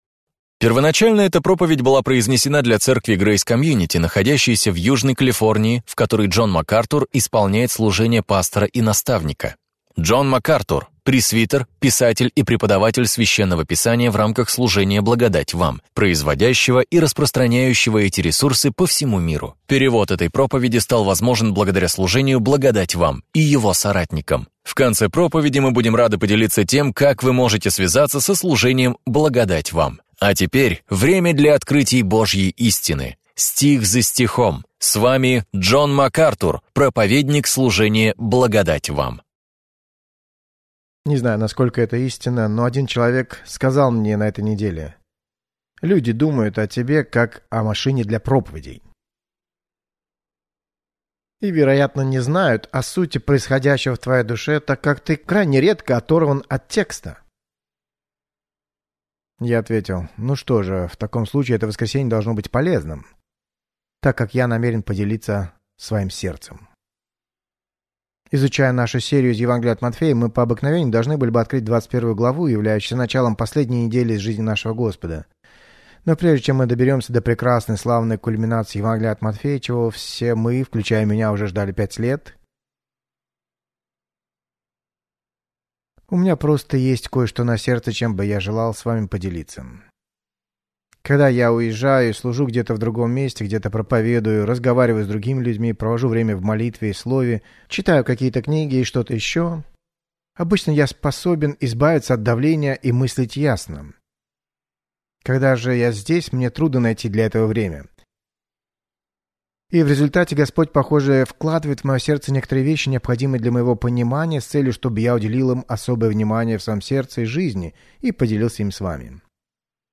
«Анатомия Церкви» – это ценная проповедь, поясняющая, как вы и ваша церковь можете прославлять Бога!